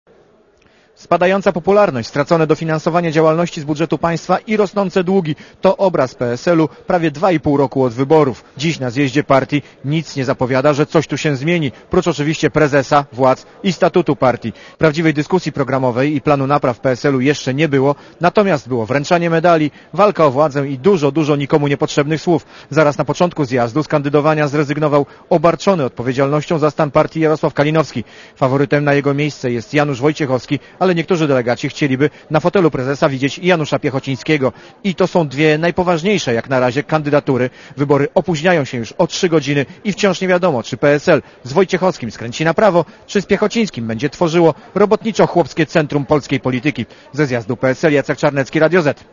Relacja reportera Radia Zet
pslkongres.mp3